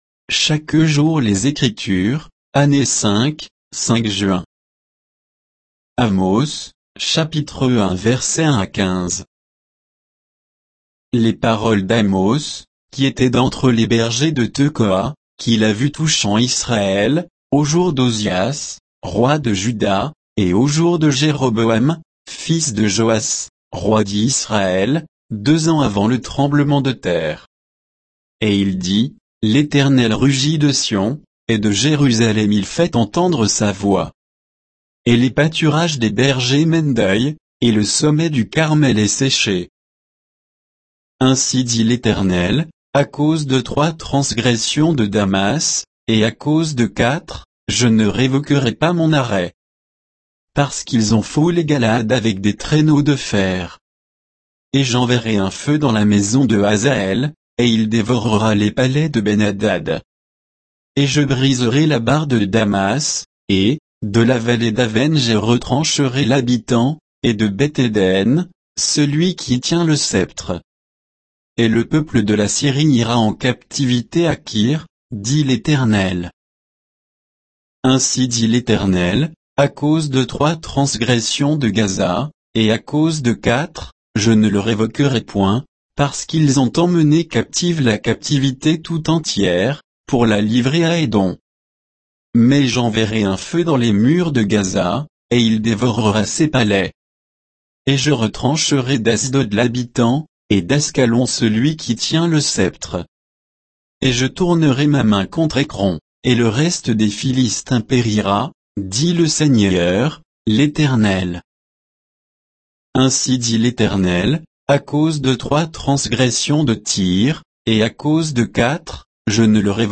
Méditation quoditienne de Chaque jour les Écritures sur Amos 1, 1 à 15